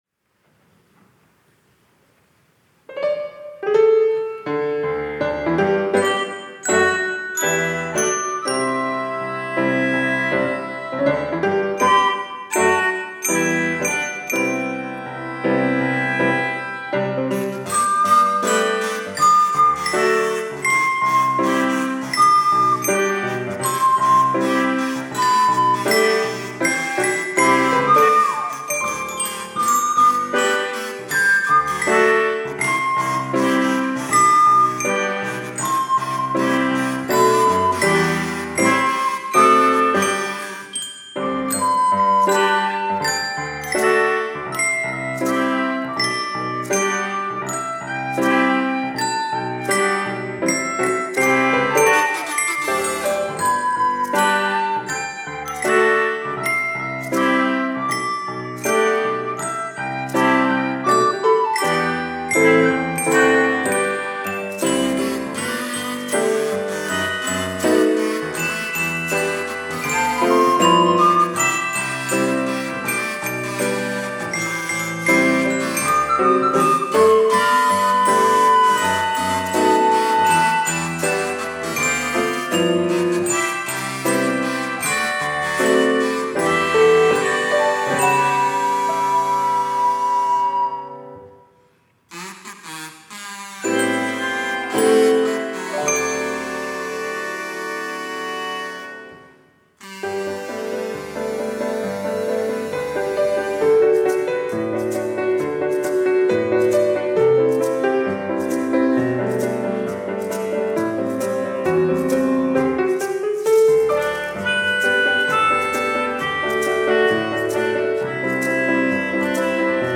특송과 특주 - 주를 찾는 모든 자들이
청년부 올려드림